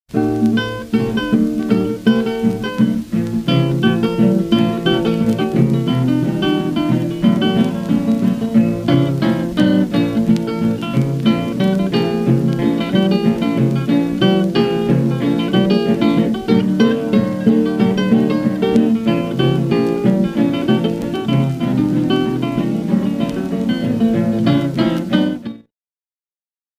BLUES2
BLUES2.mp3